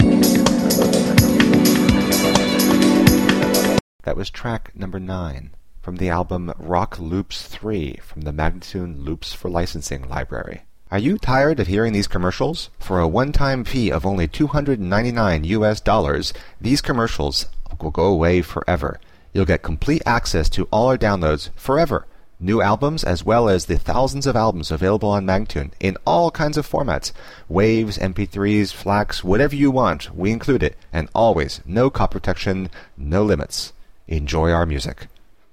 Instrumental samples in many genres.